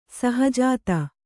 ♪ saha jāta